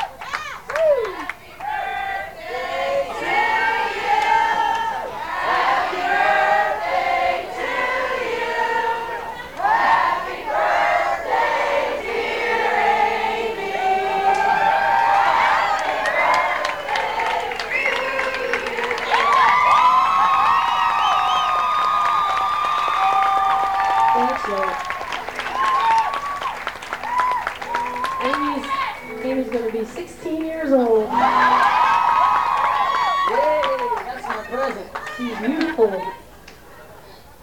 04. crowd sings happy birthday to amy (0:37)